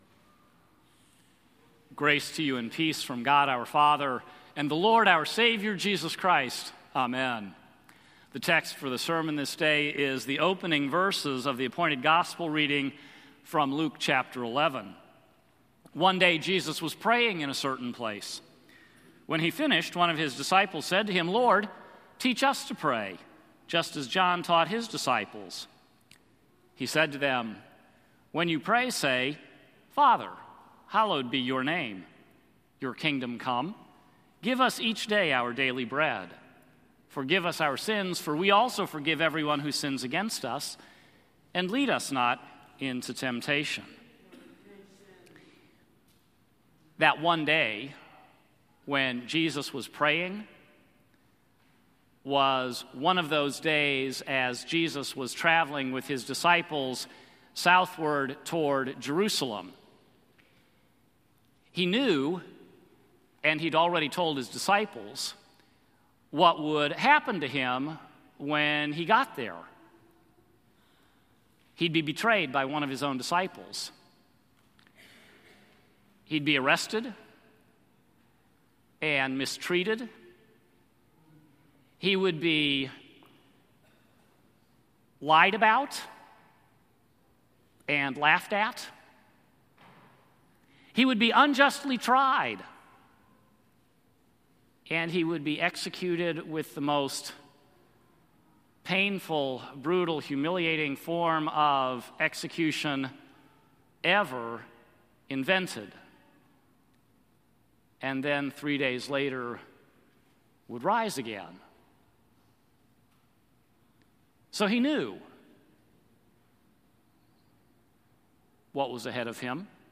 “Learning to Pray” Sermon – FAITH LUTHERAN CHURCH